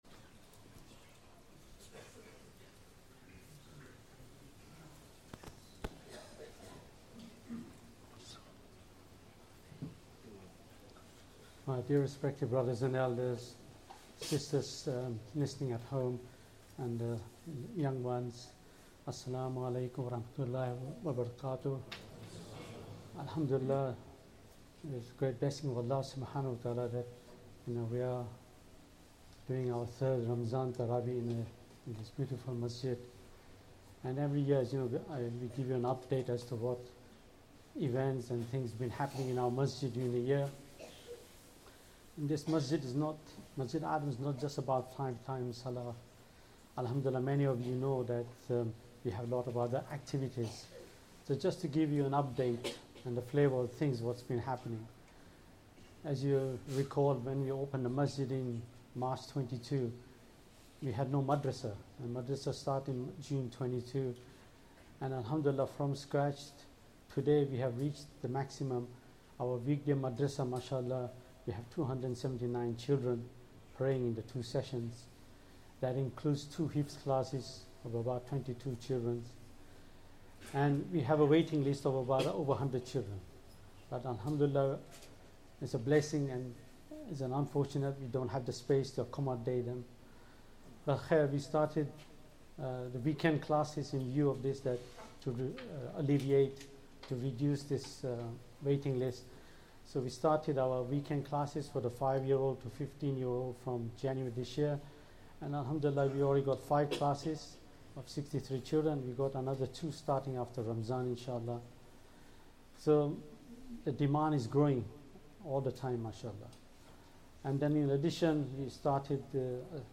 2nd Tarawih prayer - 7th Ramadan 2024